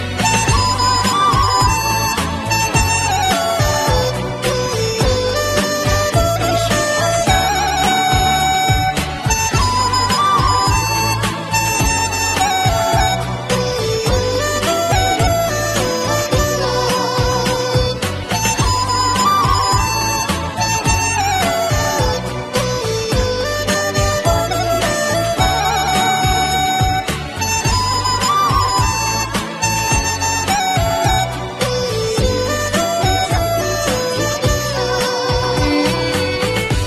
Thể loại nhạc chuông: Nhạc không lời